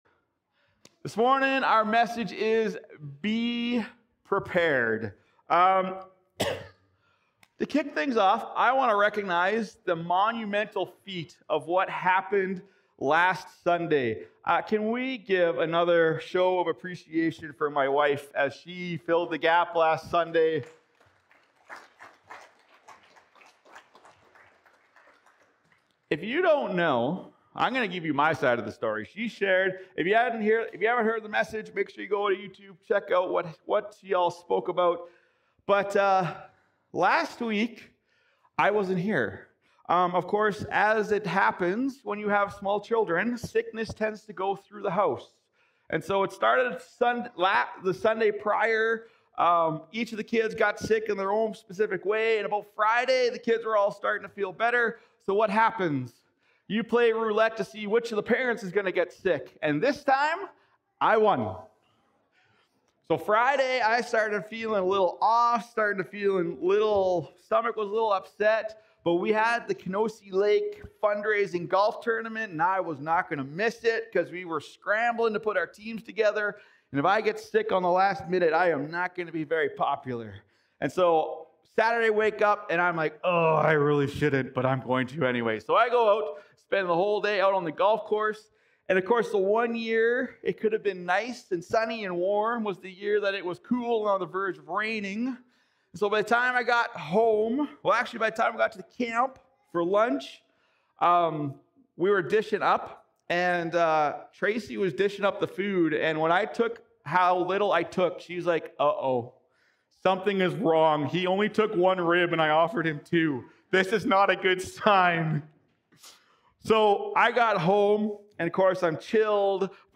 Sermons | OneChurch